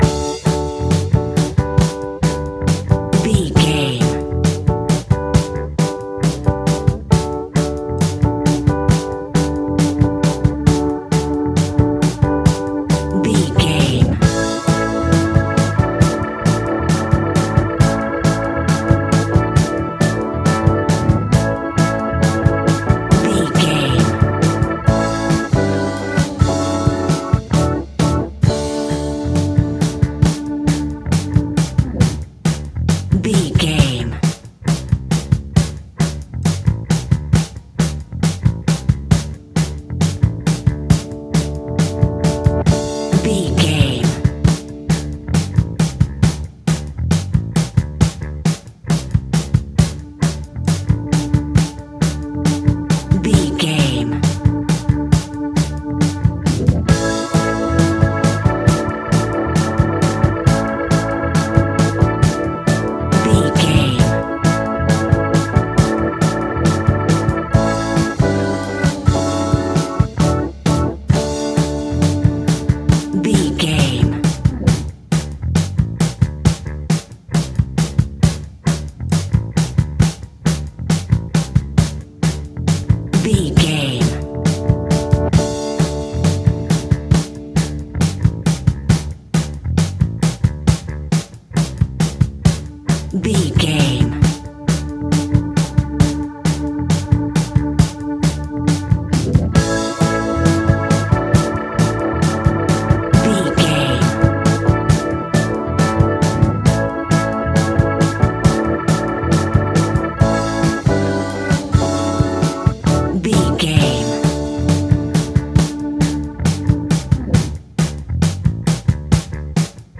Ionian/Major
funky
uplifting
bass guitar
electric guitar
organ
saxophone